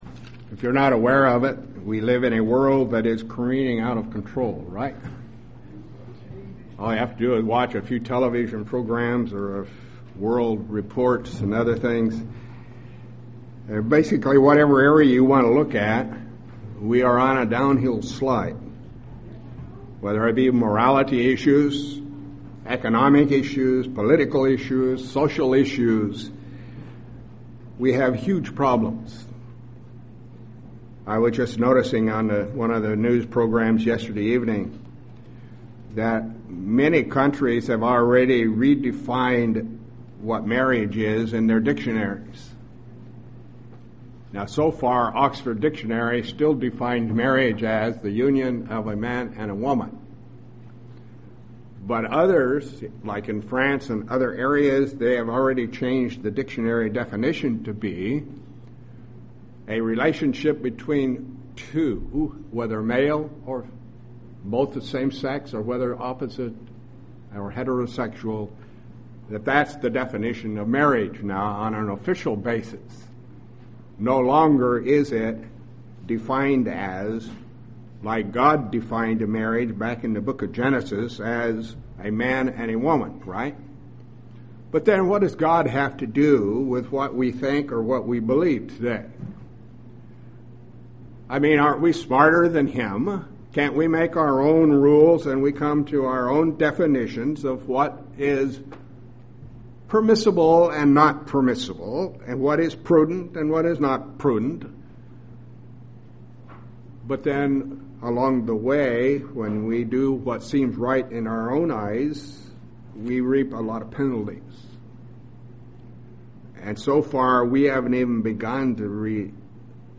There is coming a day when God will put an end to mankind's disobedience and sin, that day is the Day of the Lord. This sermon goes through bible prophecy of that day.